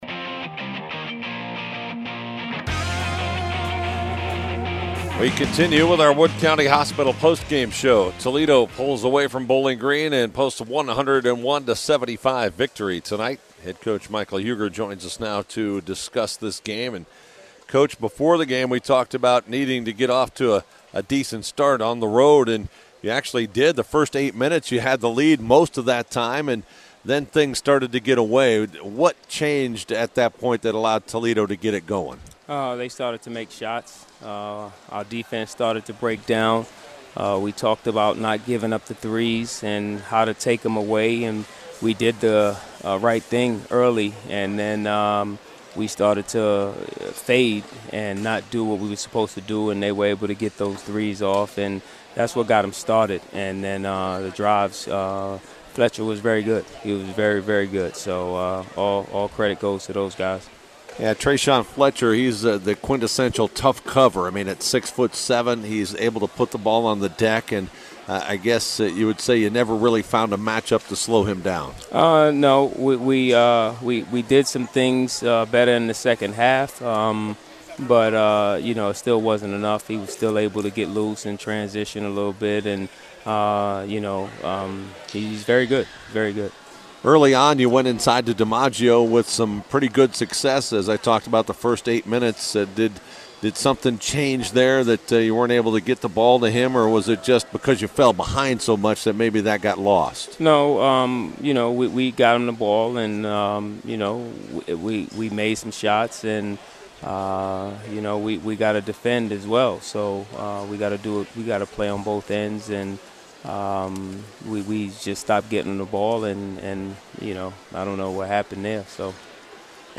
Post-Game Audio: